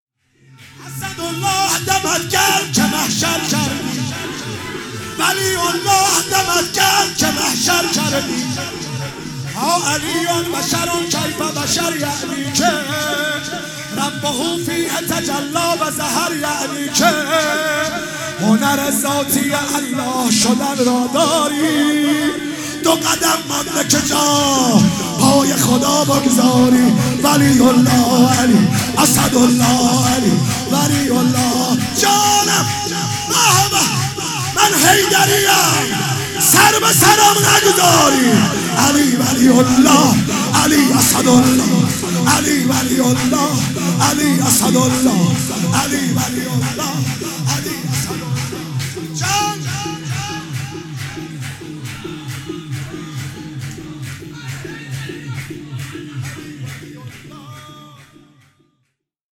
میلاد حضرت رسول اکرم (صلی الله علیه و آله) و امام صادق (علیه السلام)
شور